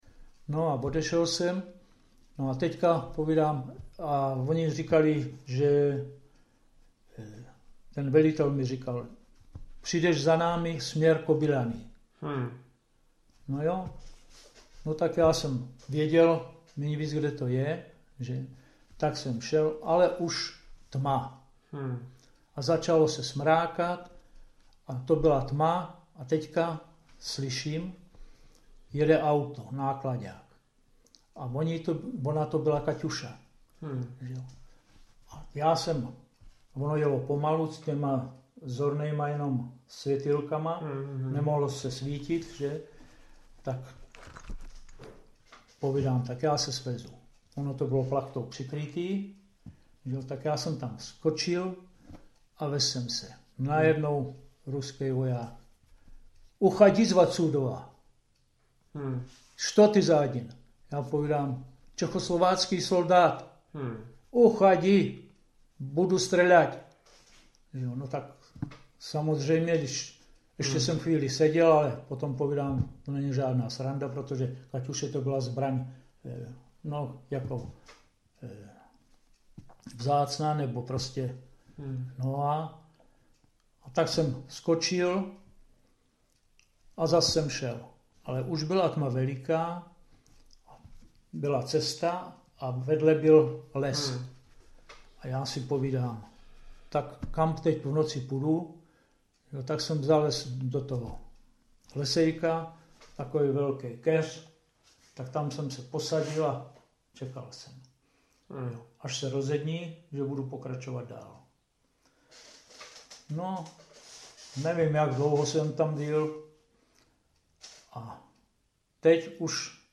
Klip "Noční dobrodružství" z vyprávění pamětníka